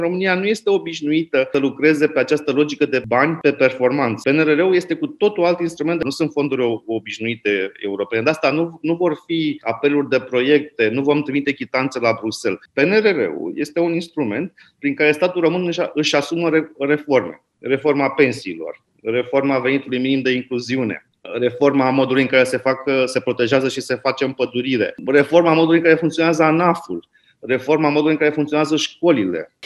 Cristian Ghinea atrage atenţia că banii din fondul de rezilienţă vor fi daţi doar pentru performanţă. Este un mecanism diferit faţă de cel al fondurilor europene structurale, spune ministrul